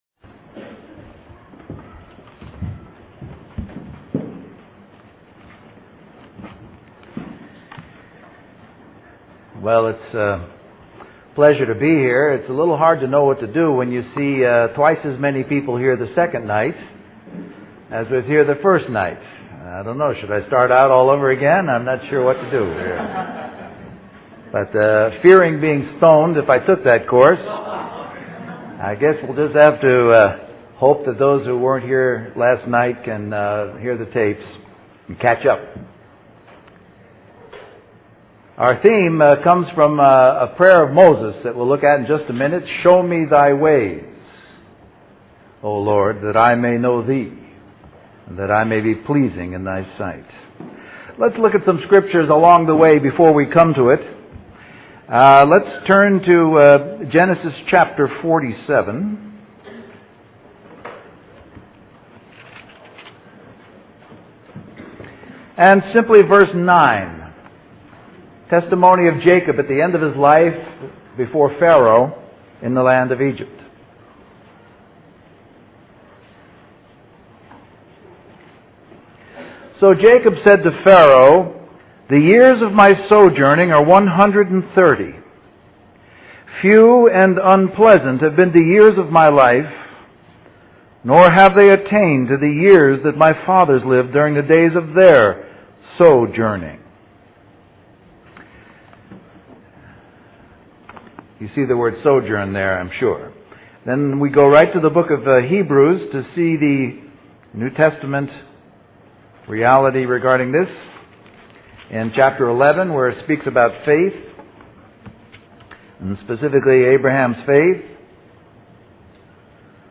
A collection of Christ focused messages published by the Christian Testimony Ministry in Richmond, VA.
Western Christian Conference